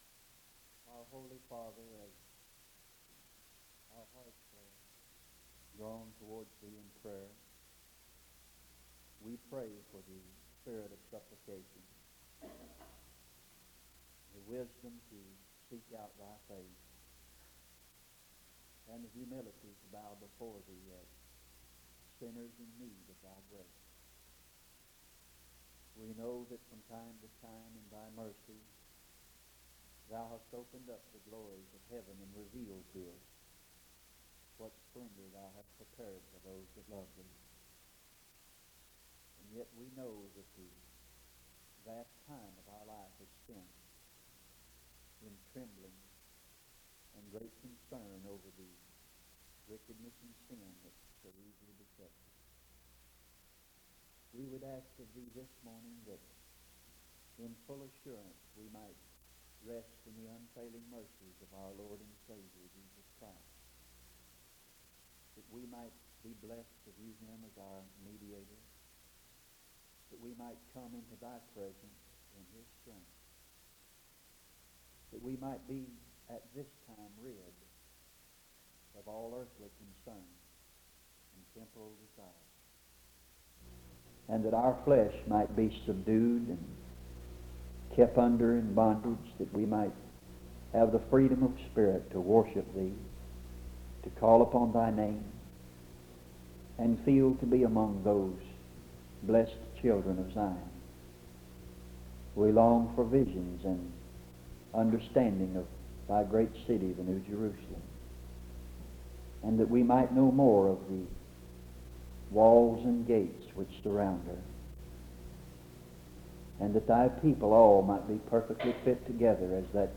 Sermons and singing from the Salisbury Association meeting from an unspecified year